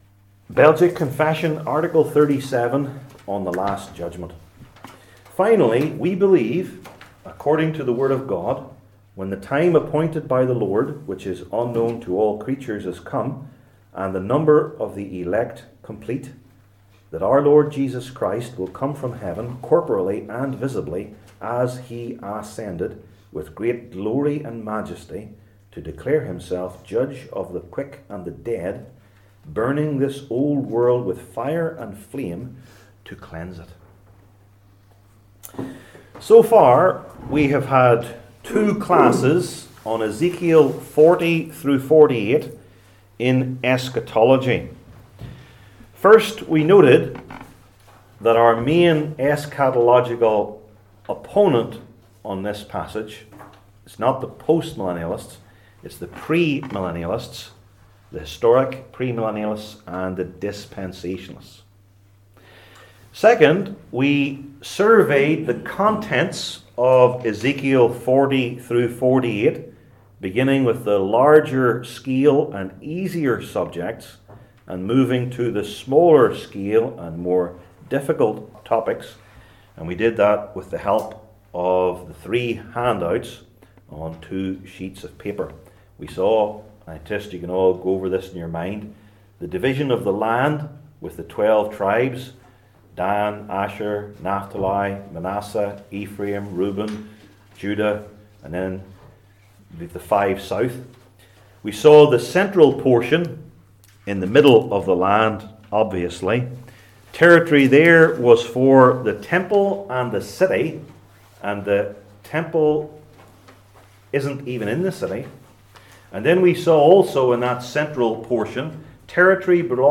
Passage: Ezekiel 42 Service Type: Belgic Confession Classes